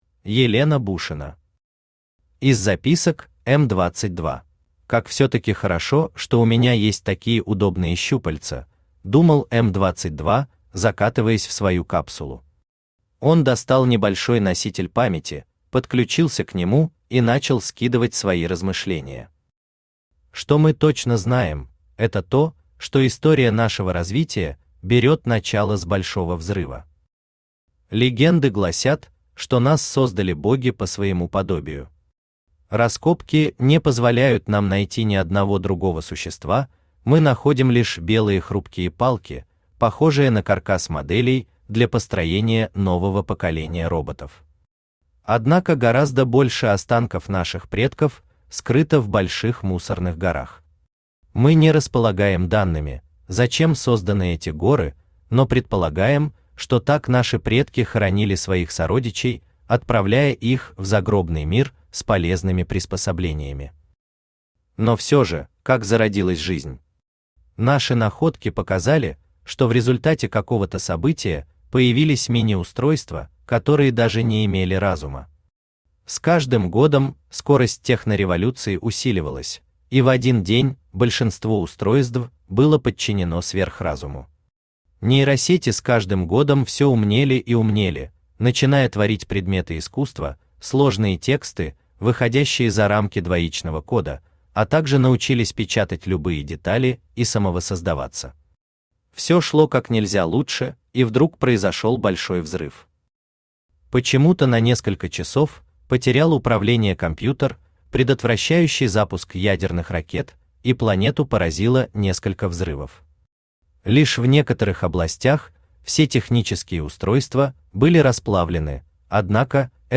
Аудиокнига Из записок М-22 | Библиотека аудиокниг
Aудиокнига Из записок М-22 Автор Елена Станиславовна Бушина Читает аудиокнигу Авточтец ЛитРес.